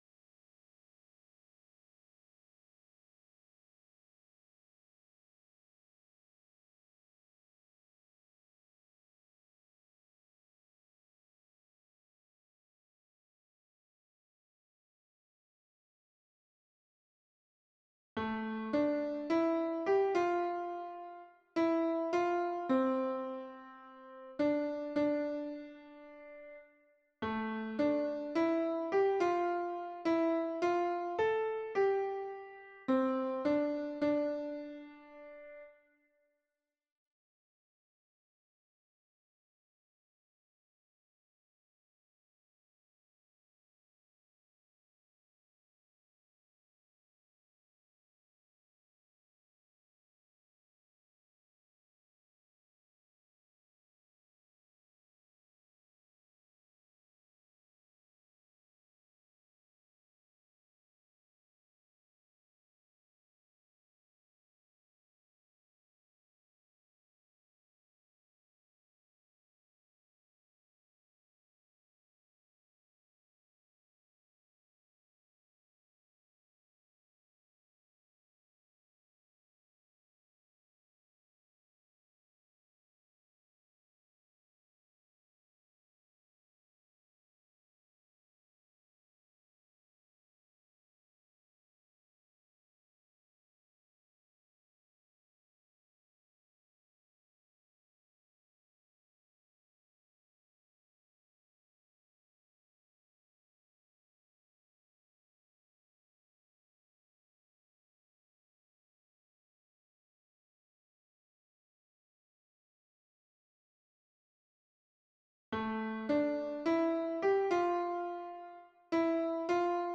MP3 version piano
Voix soliste